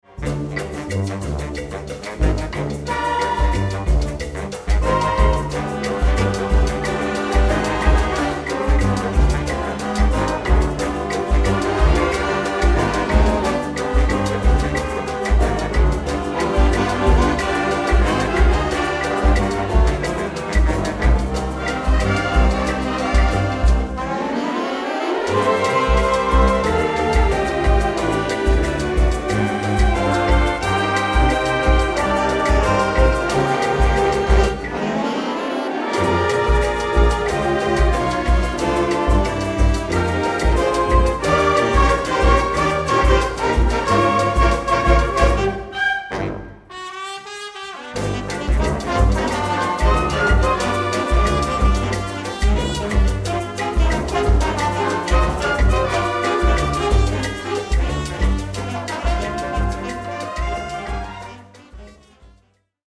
Enregistré en public au Beursschouwburg